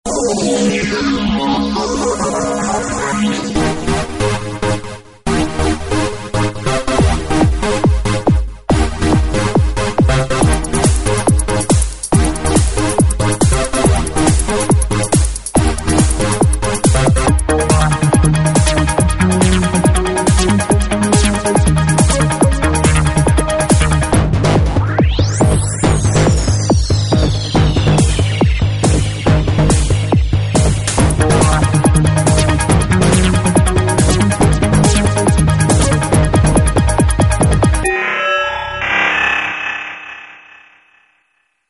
Waldorf Microwave II XT XTK - TechnoHouse